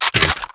Pistol_ClipIn_05.wav